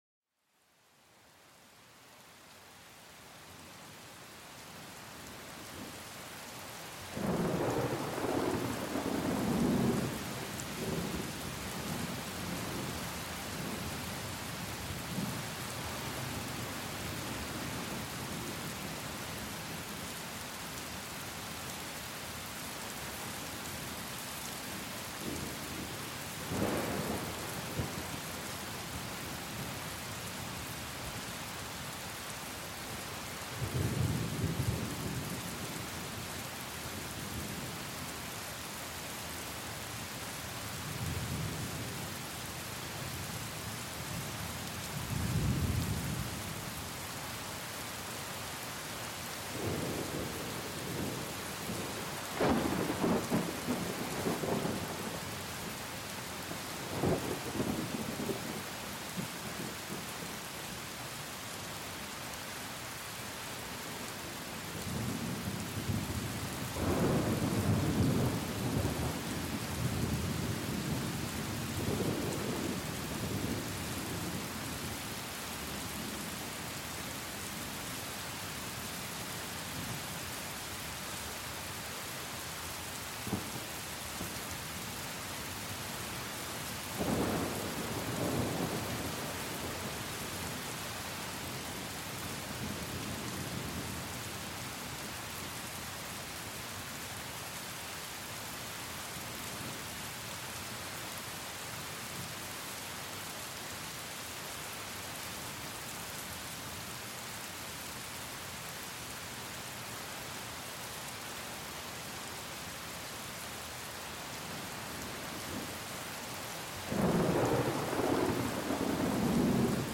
Utilizando grabaciones de alta calidad para crear una atmósfera de paz, este podcast te invita a explorar paisajes sonoros diversos.